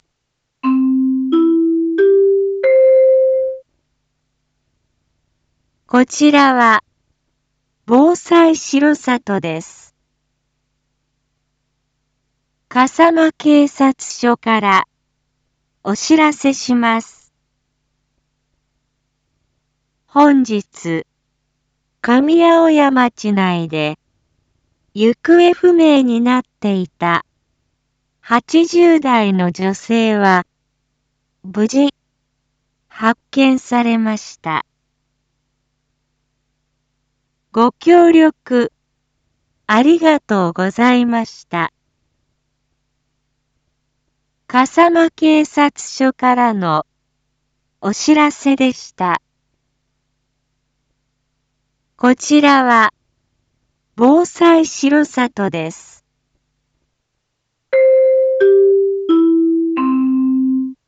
一般放送情報
Back Home 一般放送情報 音声放送 再生 一般放送情報 登録日時：2024-07-01 17:46:02 タイトル：行方不明者発見R6.7.1 インフォメーション：こちらは、防災しろさとです。